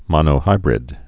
(mŏnō-hībrĭd)